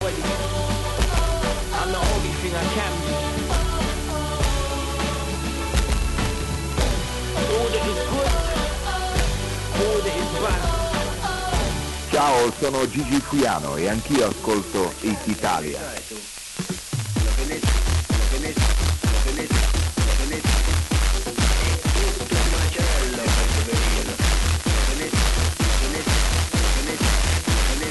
A második a 61,0 MHz-ről:
- olasz helyi stúdió-stúdió vagy stúdió-adóállomás közötti átjátszás.